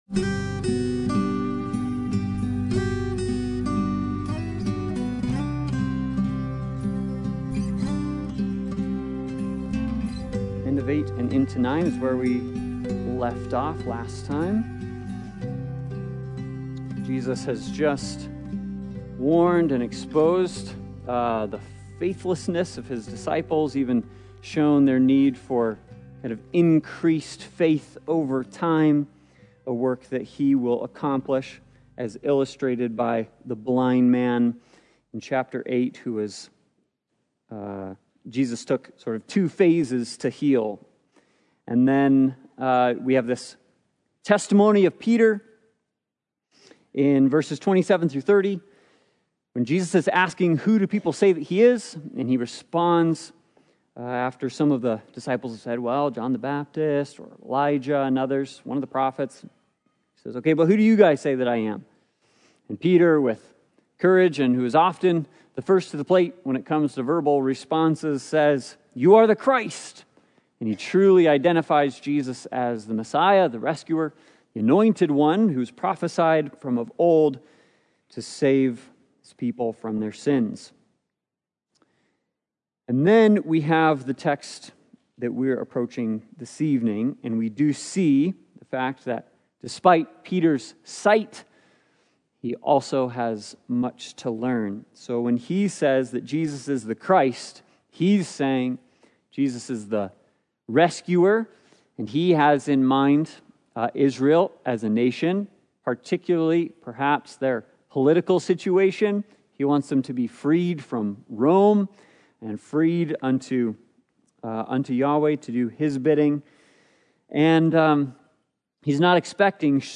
Passage: Mark 9:29 Service Type: Sunday Bible Study